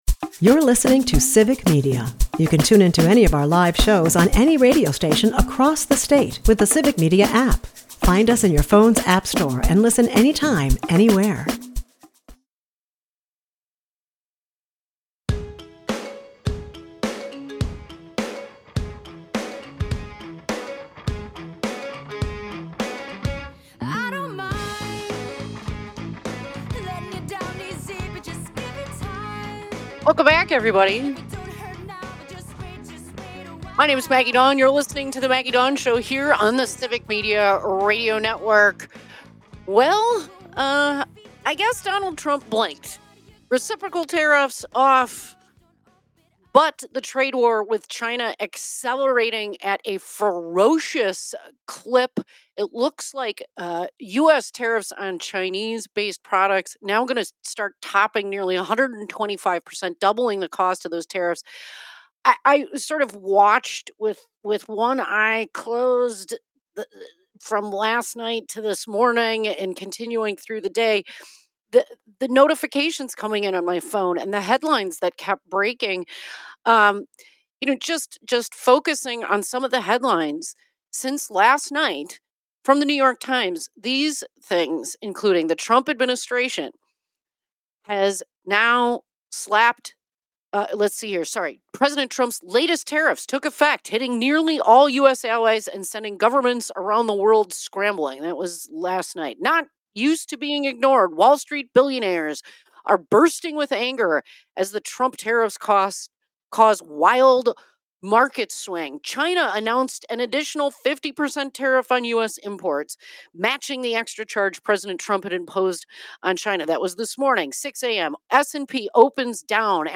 She criticizes Trump's reckless approach, which could spike pharmaceutical costs and harm middle-class Americans. The episode concludes with listeners' calls about the disastrous impacts of Trump's policies, emphasizing the need for clear, informed economic strategy.